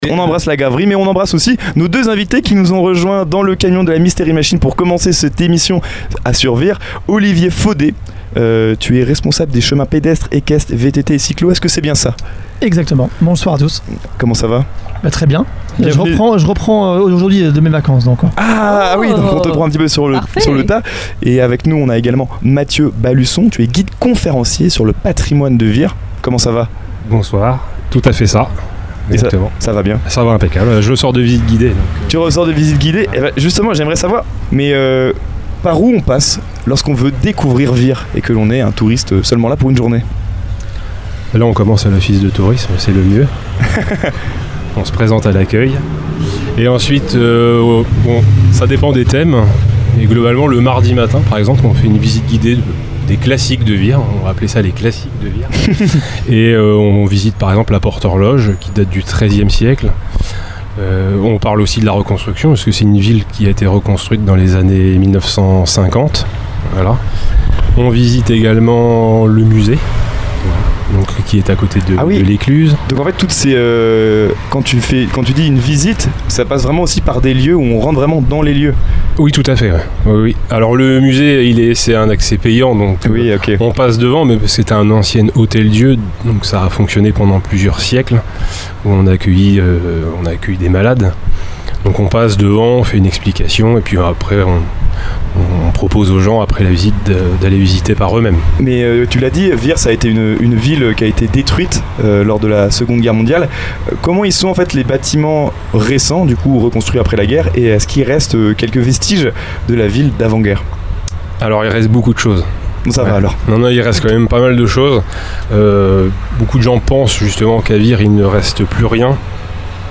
Une interview complémentaire et passionnante qui mêle nature, sport et patrimoine, et qui met en lumière toute la diversité et l’attractivité de Vire Normandie.